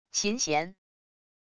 琴弦wav音频